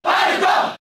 File:Falco Cheer Japanese SSBB.ogg
Falco_Cheer_Japanese_SSBB.ogg